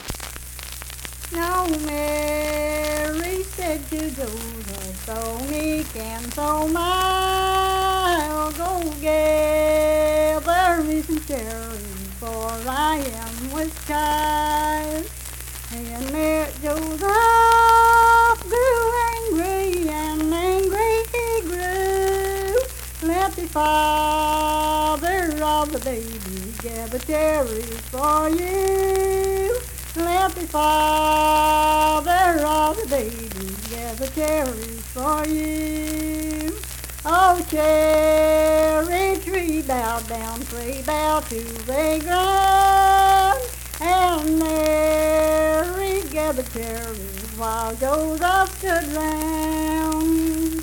Unaccompanied vocal music
Verse-refrain, 3(4).
Hymns and Spiritual Music
Voice (sung)